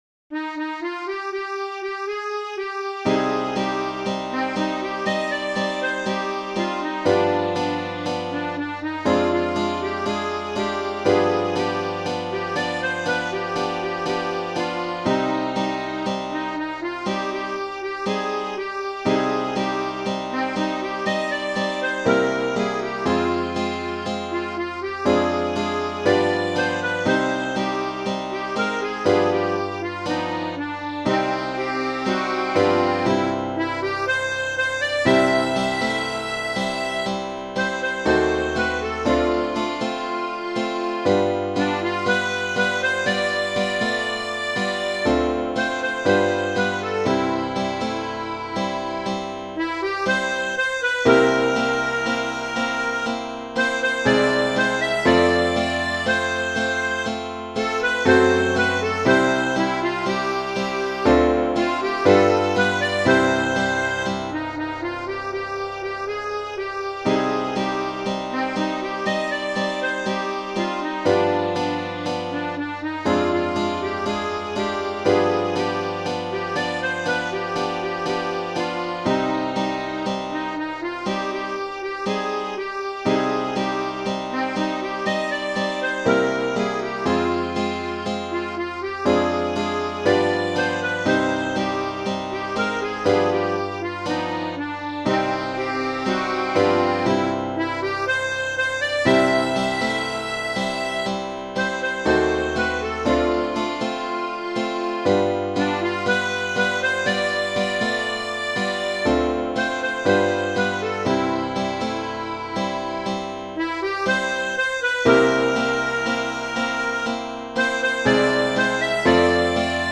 Martinez Serrano, L. Genere: Ballabili Yo la quería más que a mi vida, más que a mi madre la amaba yo; y su cariño era mi dicha, mi único goce era su amor.